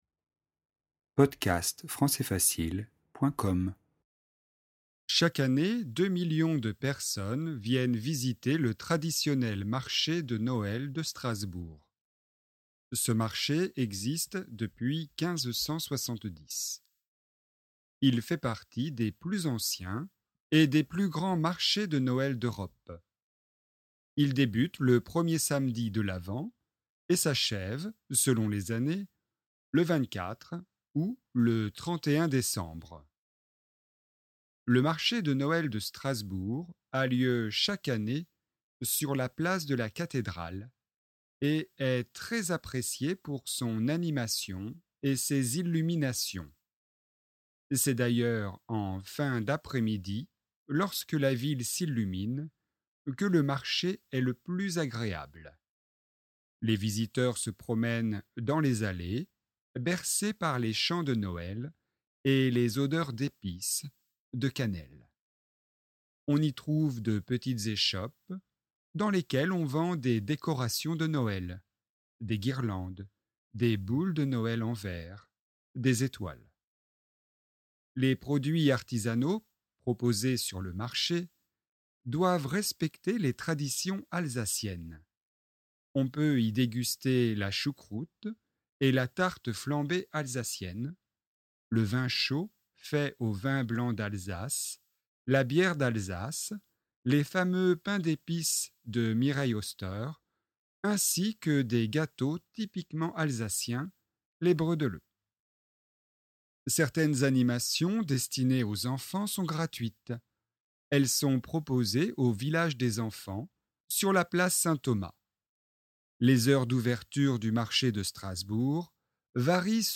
Vitesse normale :